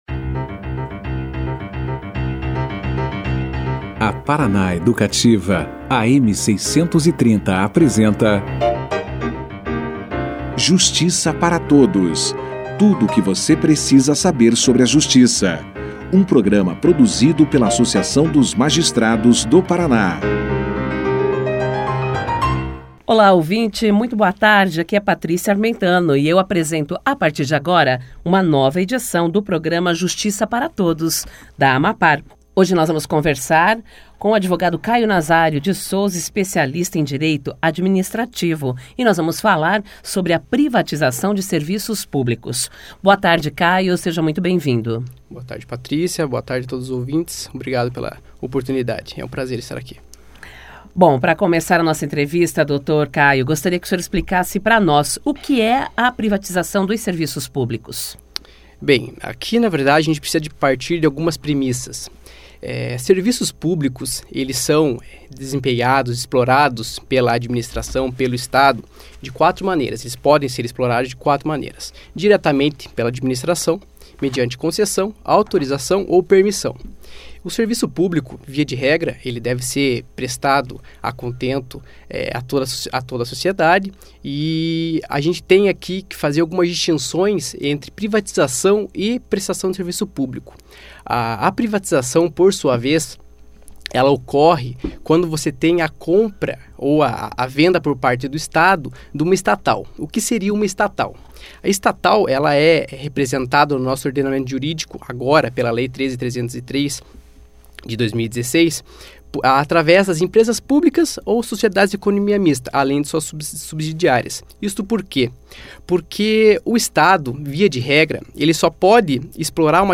Na terça-feira (08/07/2019), o programa de rádio Justiça para Todos, debateu as vantagens da privatização de serviços públicos.